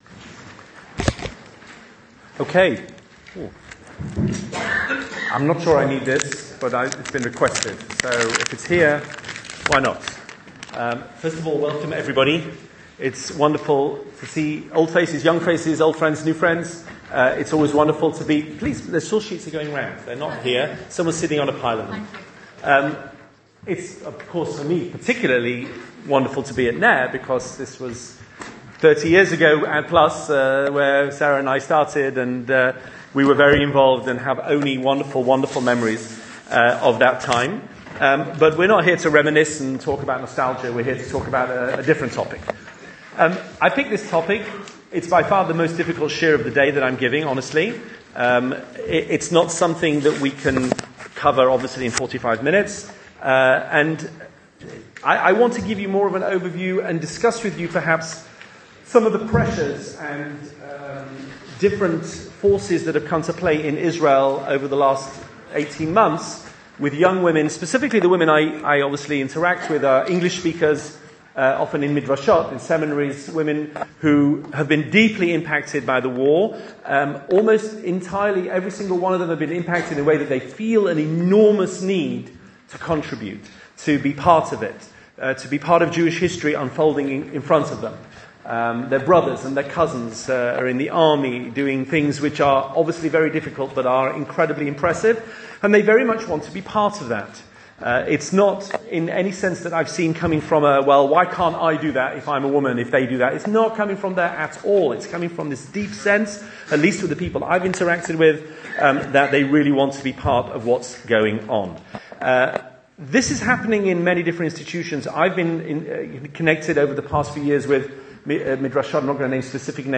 Two shiurim given in 2020: Part 1 Part 2 Shiur given in London in June 2025: